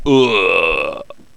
khanat-sounds-sources/sound_library/voices/death/haaaaaa/daemon_die1.wav at f42778c8e2eadc6cdd107af5da90a2cc54fada4c
daemon_die1.wav